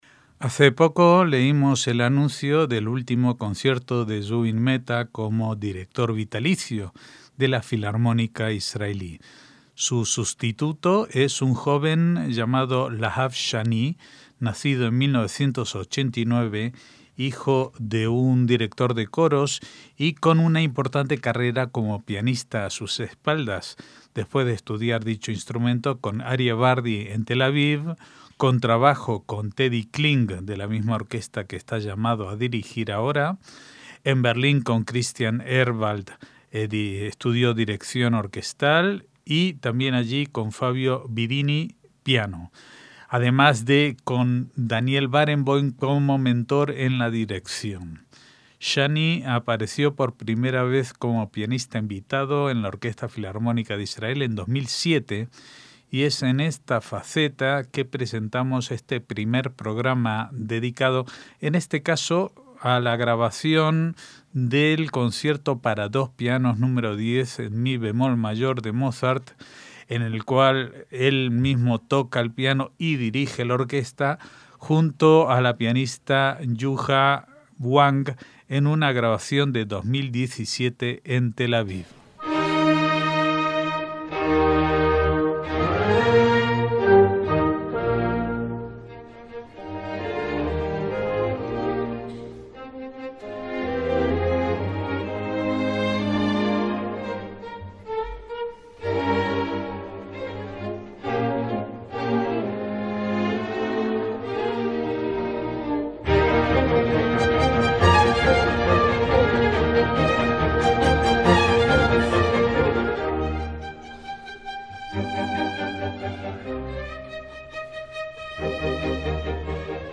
Lahav Shani y Yuja Wang en el Concerto para dos pianos Nº 10 de Mozart
MÚSICA CLÁSICA - Hace poco leímos el anuncio del último concierto de Zubin Mehta como “director vitalicio” de la Filarmónica Israelí.